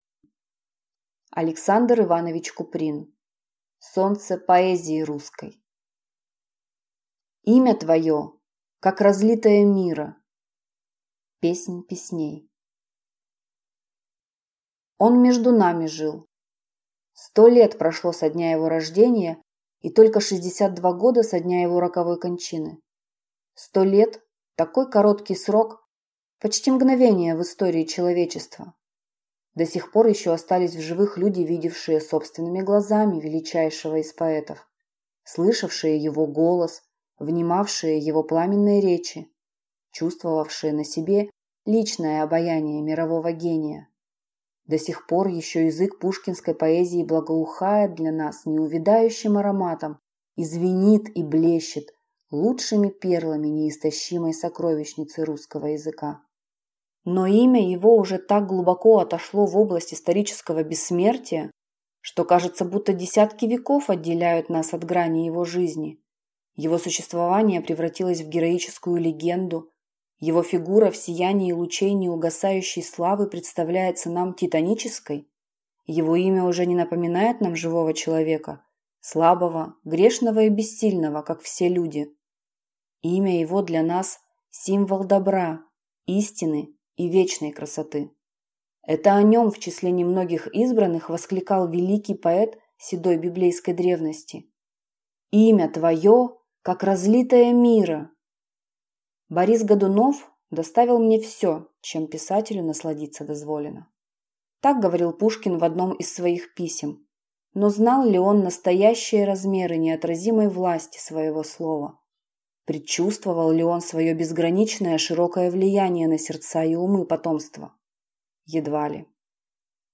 Аудиокнига Солнце поэзии русской | Библиотека аудиокниг